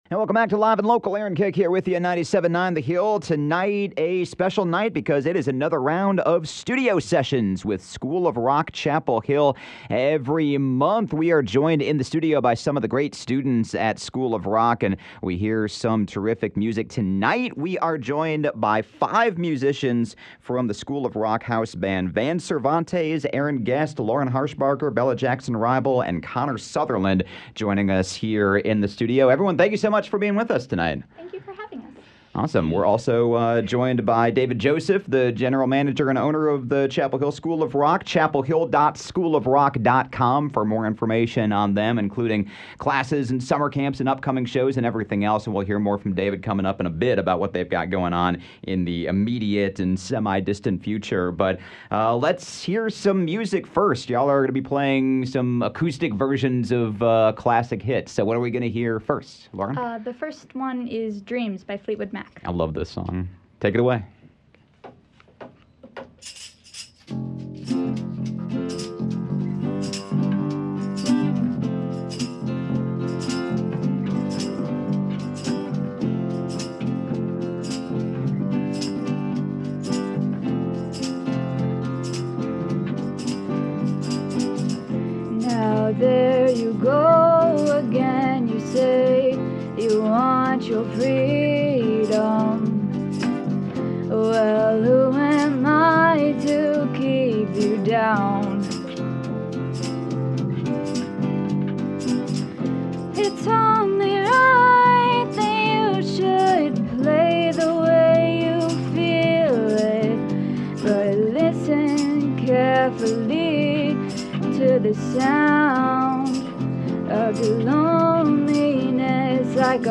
classic hits
acoustic versions of three classic hits